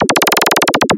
Sons et bruitages de jeux vidéos